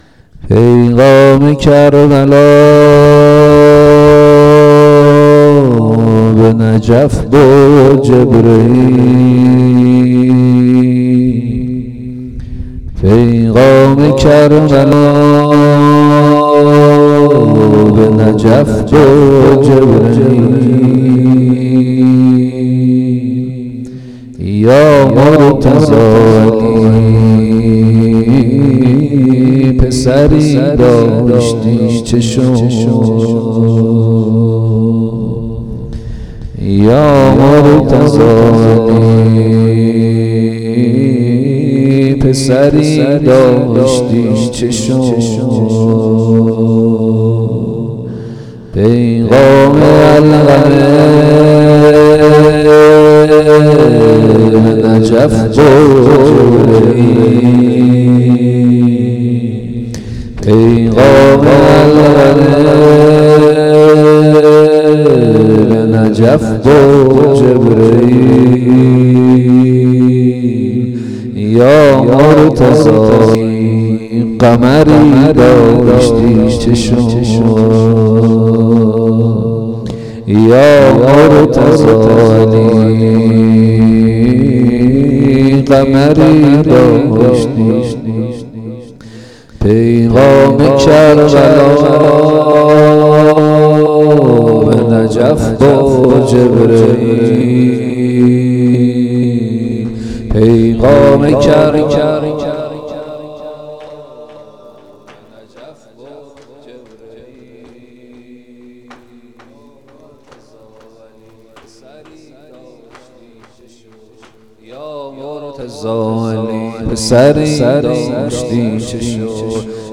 هیات انصارالحسین (ع)